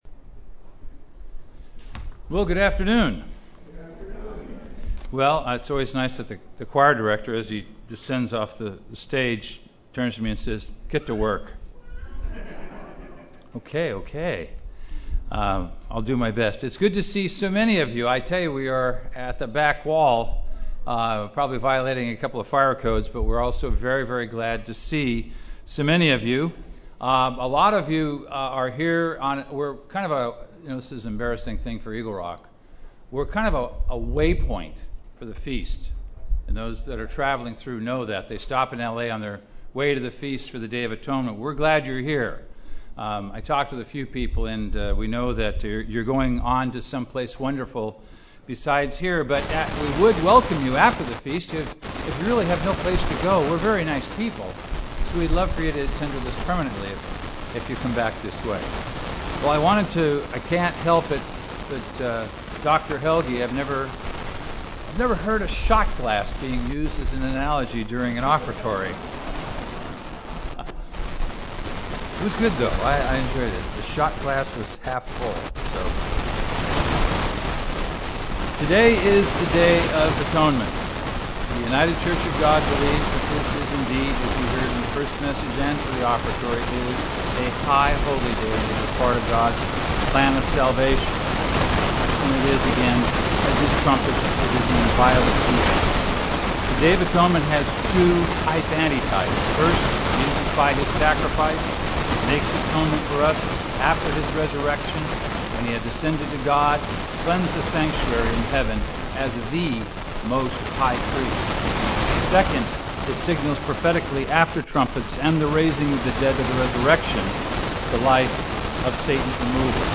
Note: There is a period of static that begins 49 seconds from the start of the recording and lasts for approximately 76 seconds.
UCG Sermon Studying the bible?